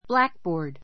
blackboard A2 blǽkbɔː r d ブ ら ク ボー ド 名詞 黒板 ⦣ 緑色のものも一般 いっぱん 的には green blackboard （緑の黒板）といわれている.